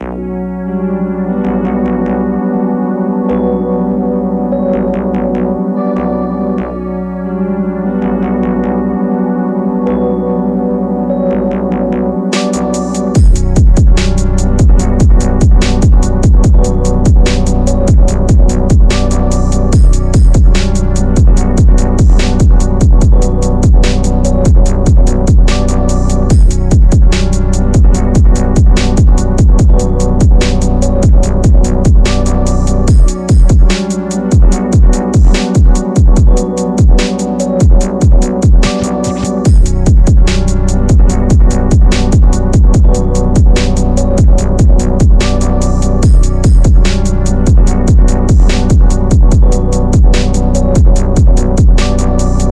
Жанр: Phonk, Wave phonk, Rap, Hip-Hop
Memphis Мрачный 73 BPM